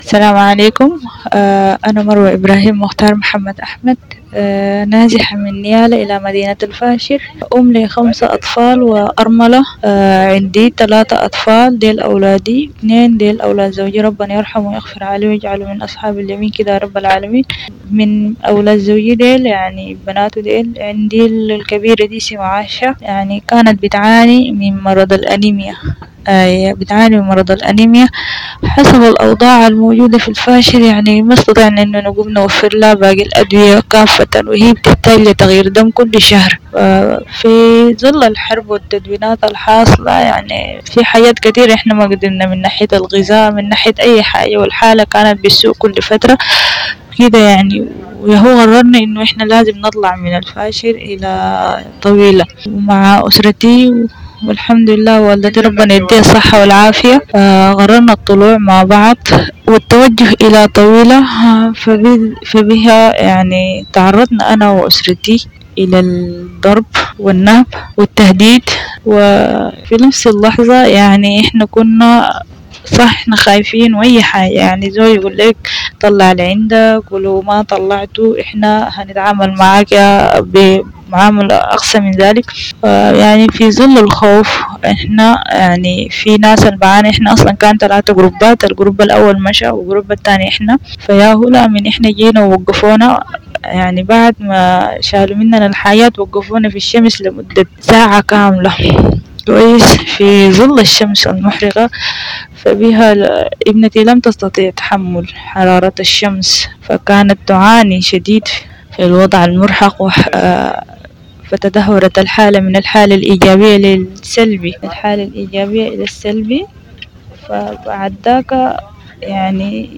نازحة-من-نيالا.mp3